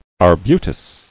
[ar·bu·tus]